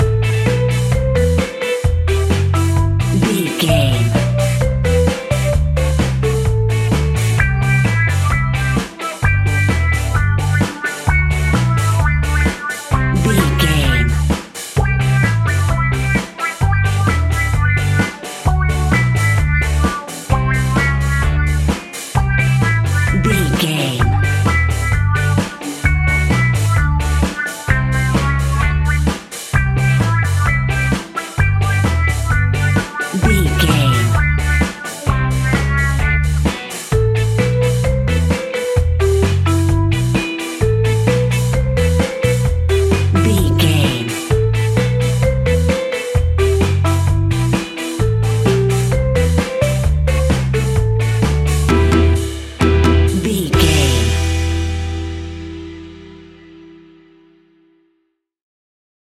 Classic reggae music with that skank bounce reggae feeling.
Aeolian/Minor
instrumentals
laid back
chilled
off beat
drums
skank guitar
hammond organ
percussion
horns